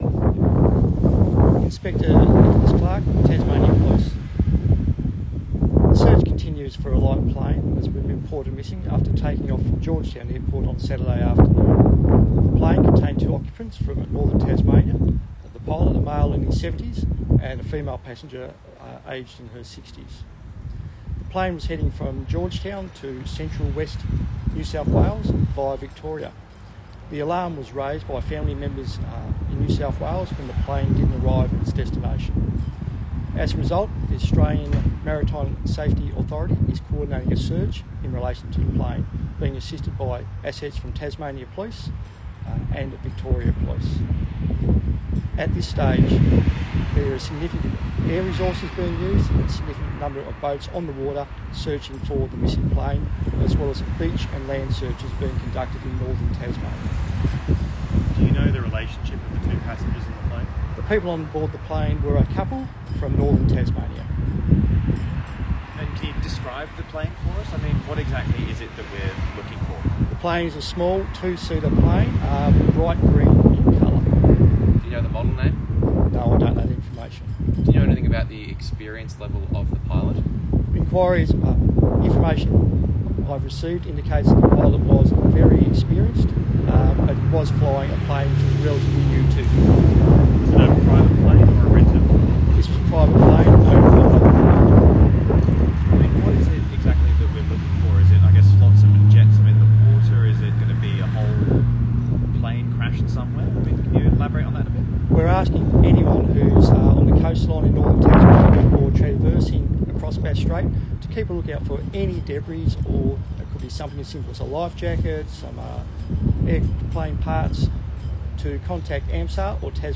provided an update to media on Sunday afternoon